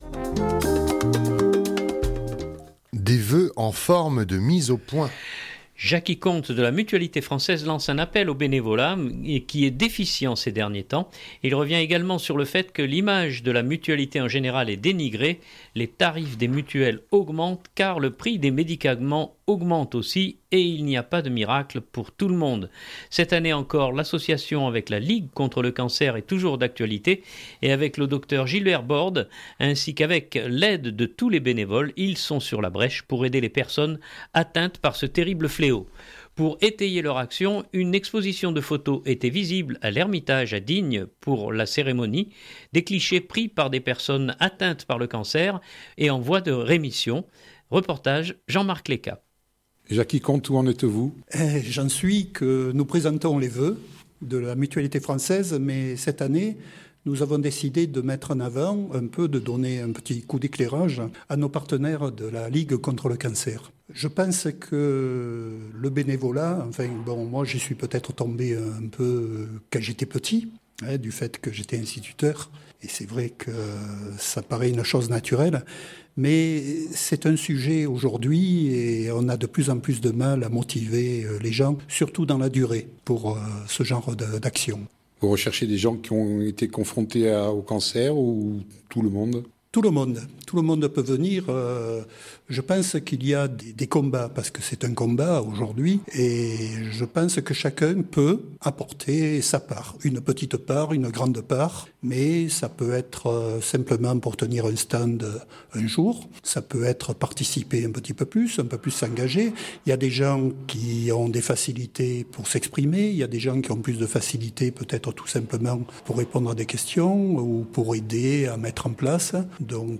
Pour étayer leur action, une exposition de photos était visible à l'Ermitage à Digne pour la cérémonie.